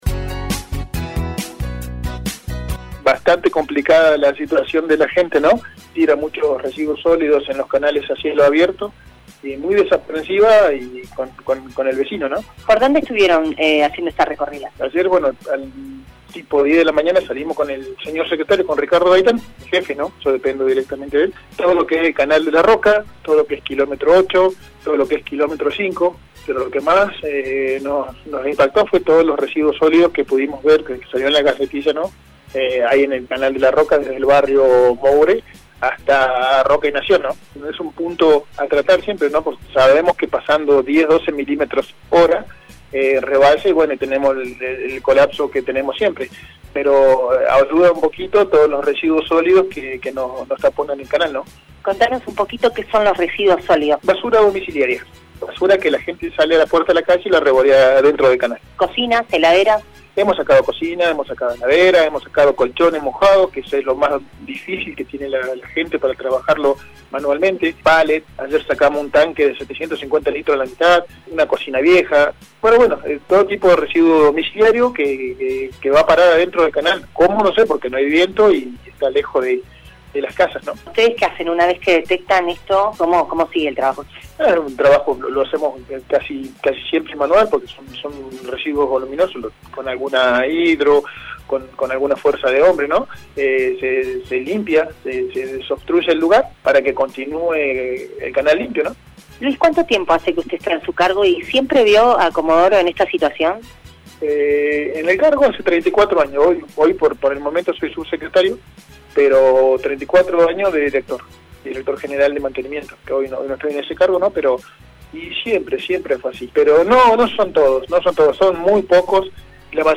Así lo comentó el subsecretario de Mantenimiento de la Municipalidad, Luis Correa: